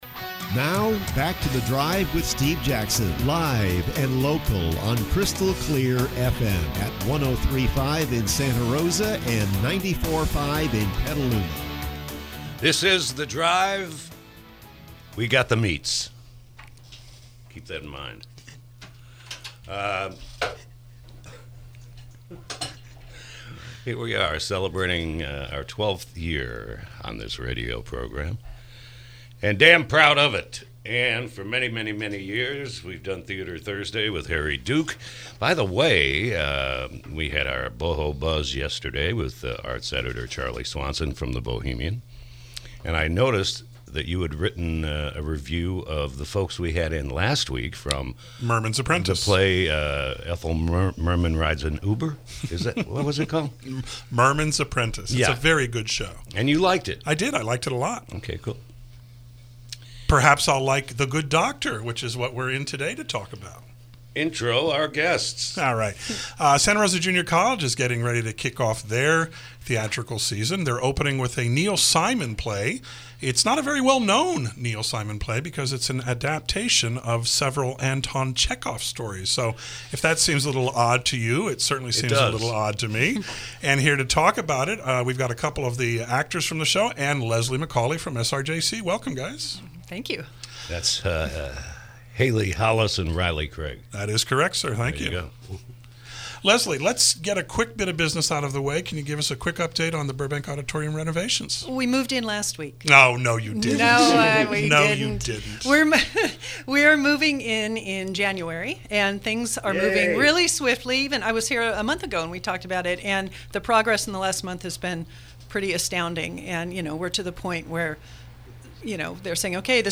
KSRO Interview: “The Good Doctor”